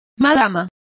Complete with pronunciation of the translation of madam.